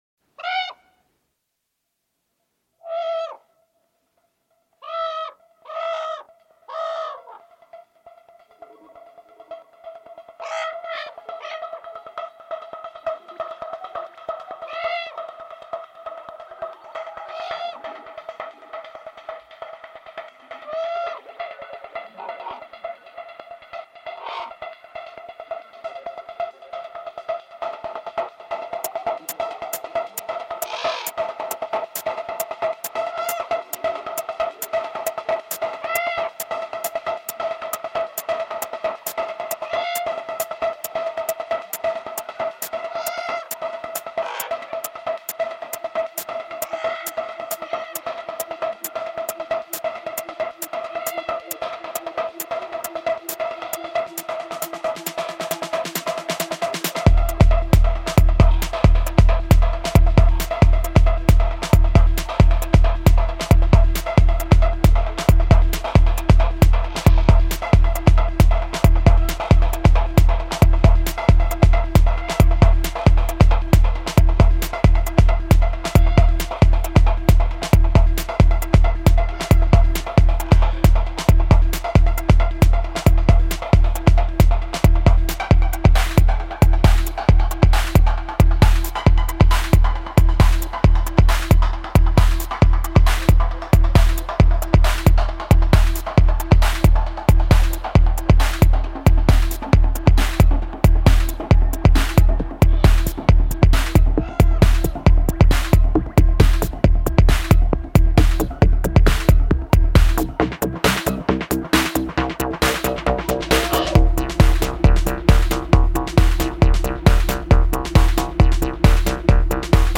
I also felt that techno was an appropriate genre to capture the contrasting aspects of ravens between their darkness and playfulness.